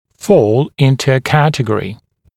[fɔːl ‘ɪntə ə ‘kætəg(ə)rɪ][фо:л ‘инту э ‘кэтэг(э)ри]попадать в категорию